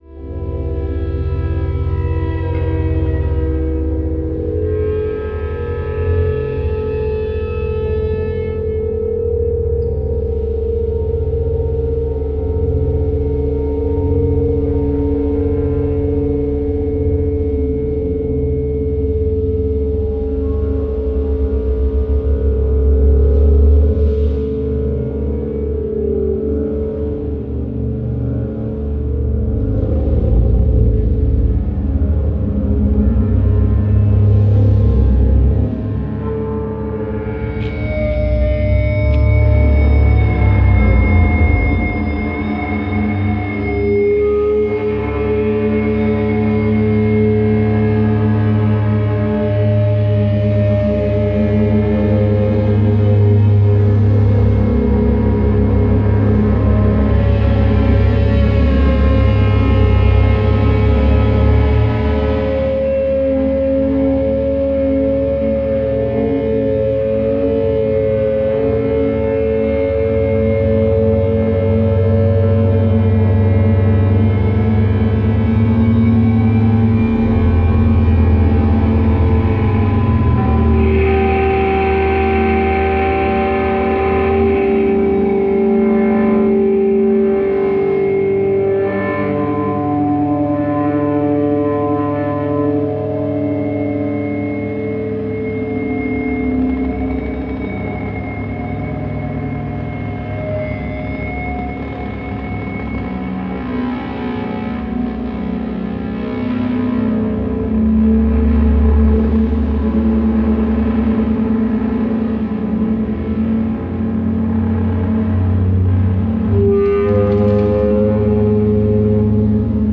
This LP was recorded in a Berlin church